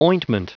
Prononciation du mot ointment en anglais (fichier audio)
Prononciation du mot : ointment